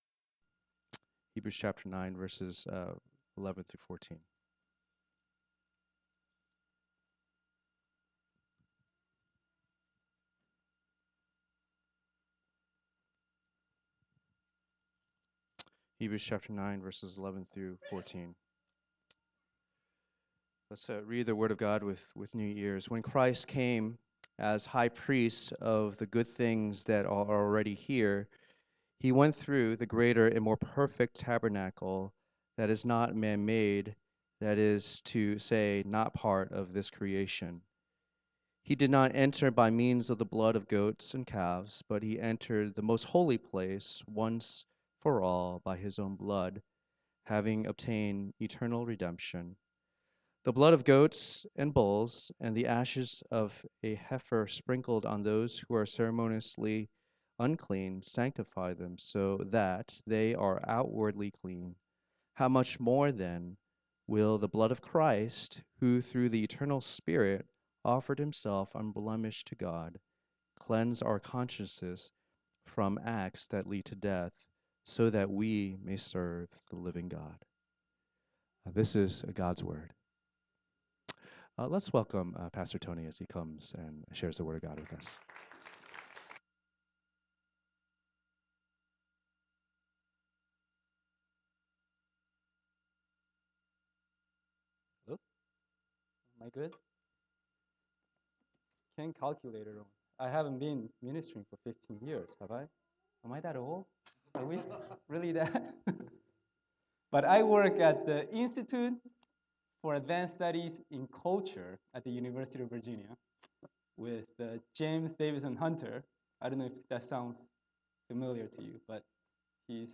Hebrews 9:11-14 Service Type: Lord's Day %todo_render% « Who the Heck is Melchizedek?